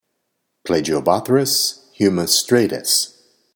Pronunciation/Pronunciación:
Pla-gi-o-bó-thrys hu-mi-strà-tus